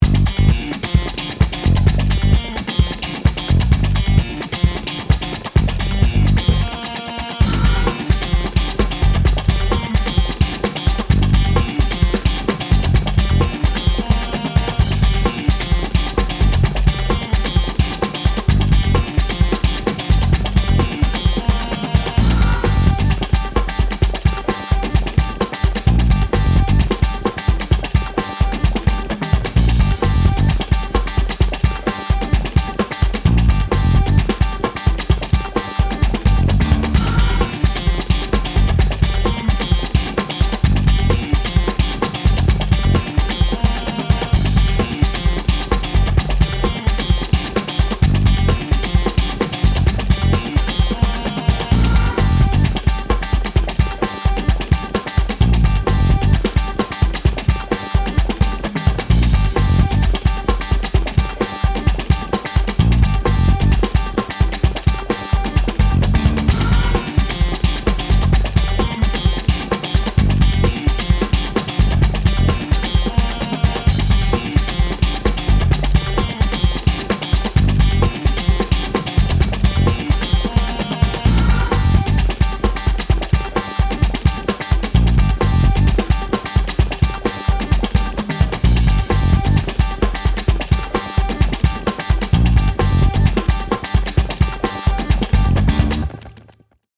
カッティングギターがかっこいいBGM
ファンク 1:36 ダウンロード